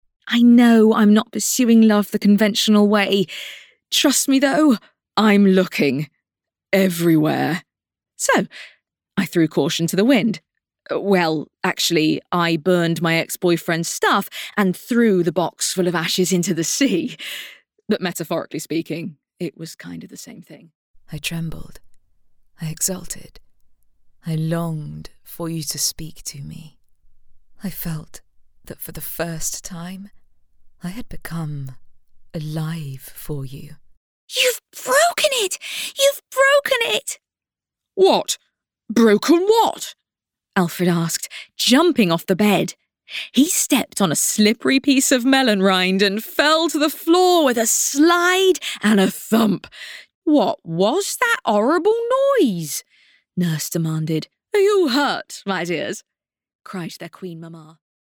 Narration
Professionally built studio.
Mezzo-Soprano
WarmConversationalFriendlyClearProfessionalConfidentWitty